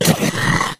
die_1.ogg